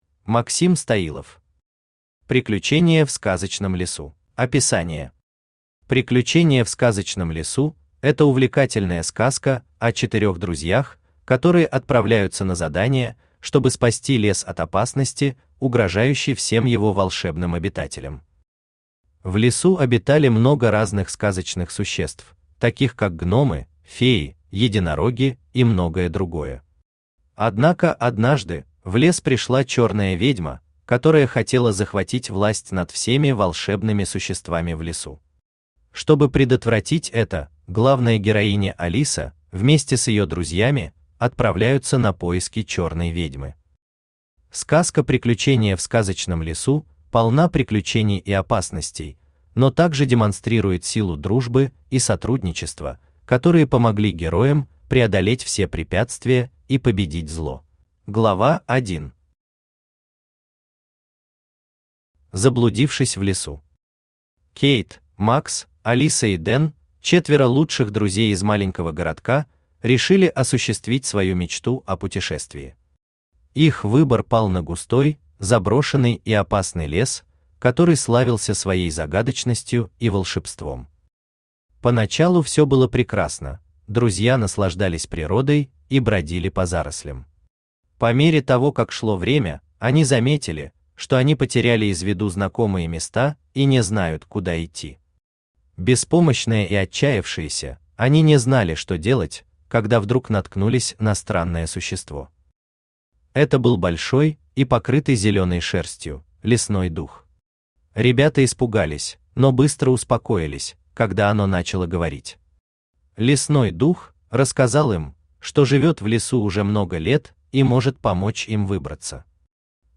Аудиокнига Приключения в Сказочном лесу | Библиотека аудиокниг